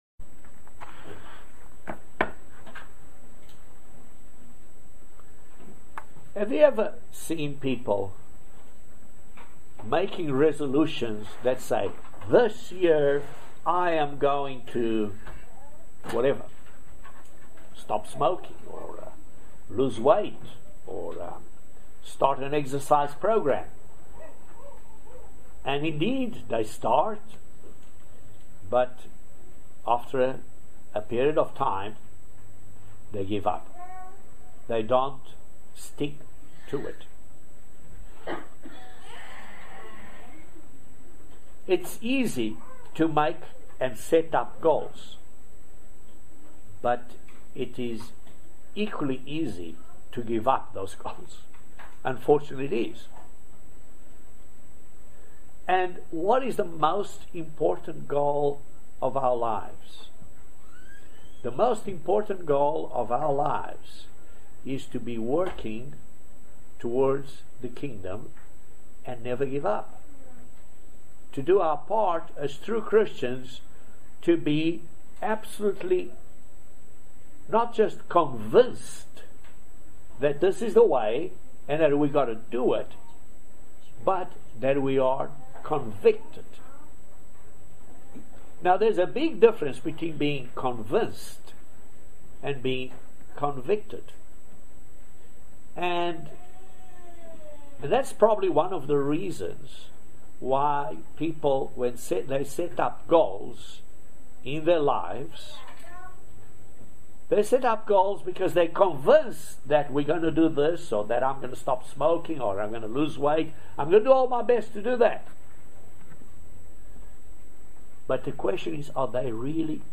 Join us for this excellent video Sermon about being convicted and not just being convinced. Can we be convinced and still not be convicted?